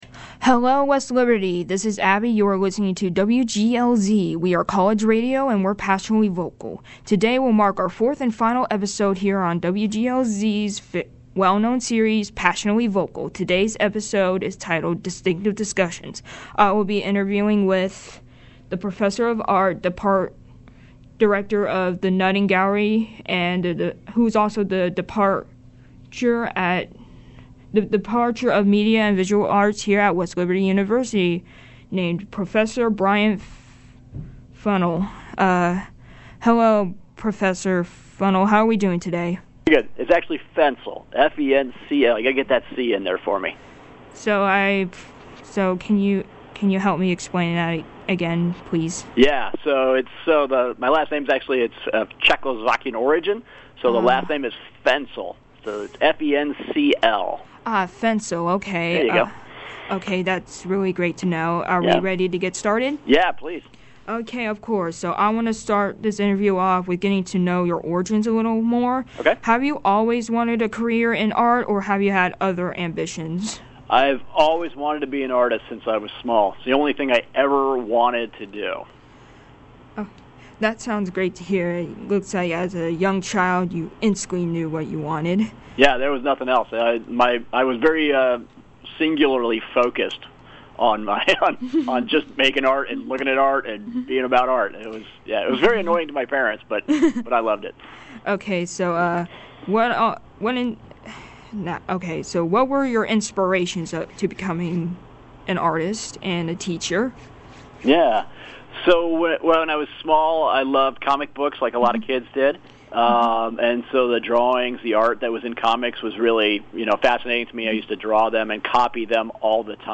Distinctive Discussion